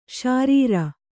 ♪ śarīra